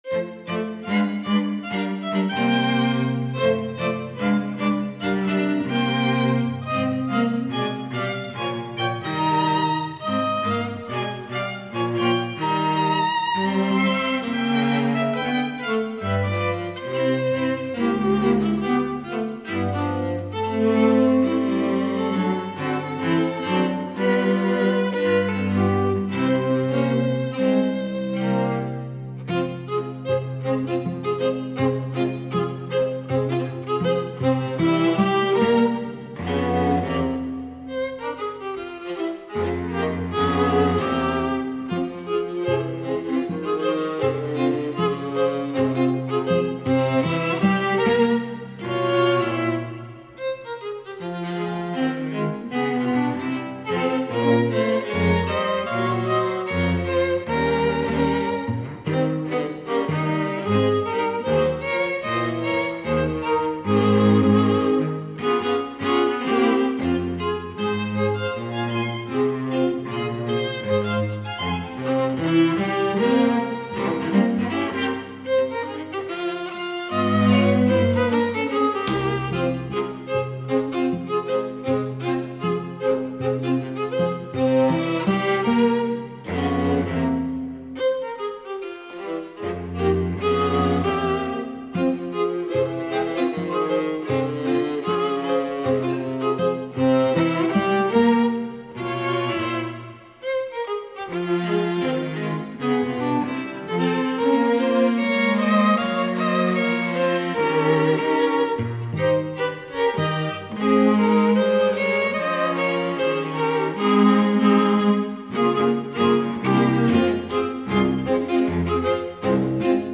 THE QUARTET
Jazz